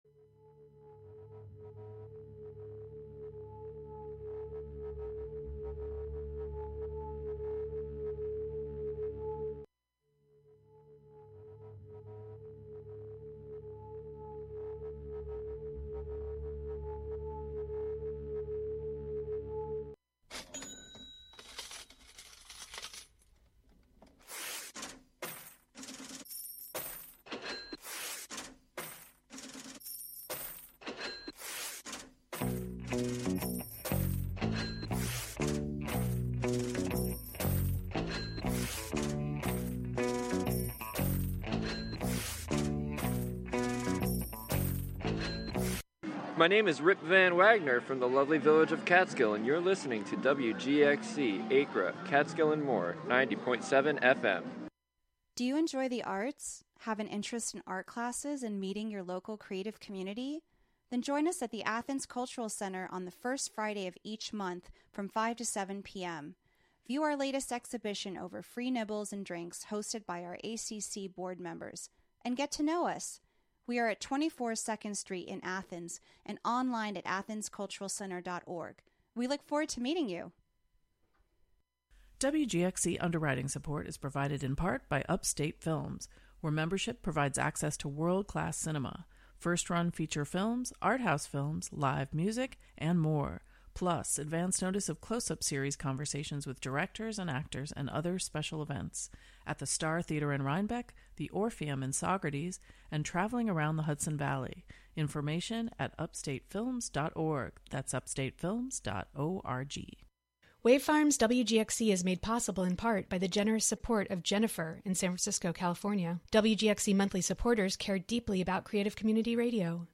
Contributions from many WGXC programmers.
Local state Sen. Michelle Hinchey was among those backing the efforts to expand the free meal program. The "WGXC Morning Show" features local news, interviews with community leaders and personalities, a rundown of local and regional events, weather updates, and more about and for the community.